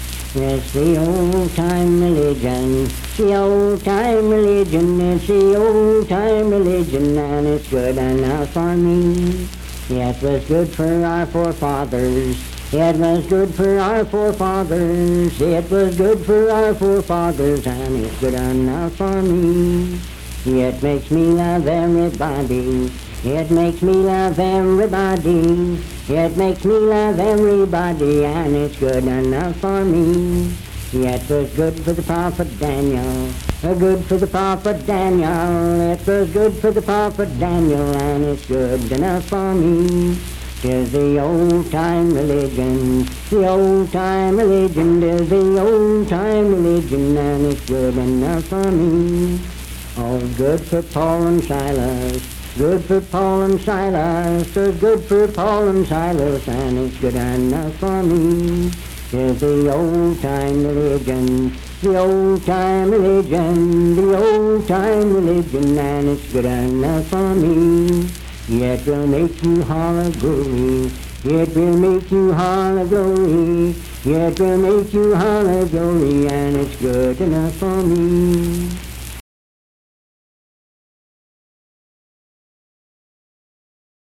Unaccompanied vocal music
Verse-refrain 5(4)&R(4).
Performed in Sandyville, Jackson County, WV.
Hymns and Spiritual Music
Voice (sung)